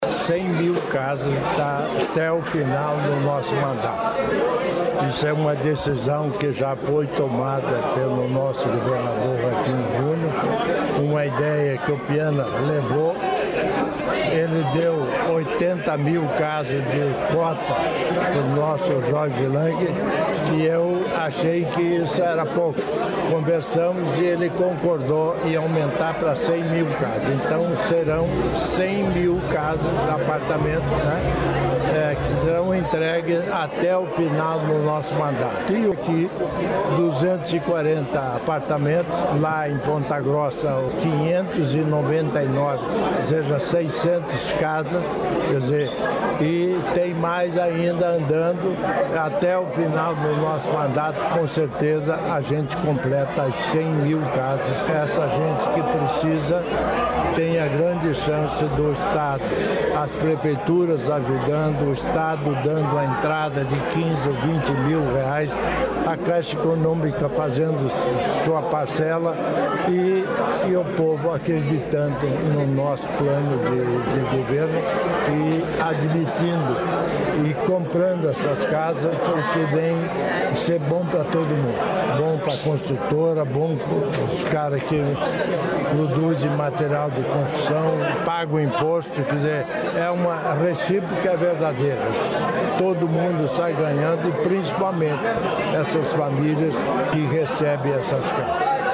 Sonora do vice-governador, Darci Piana, sobre a entrega residencial de 240 unidades em Londrina | Governo do Estado do Paraná
DARCI PIANA - COHAPAR LONDRINA.mp3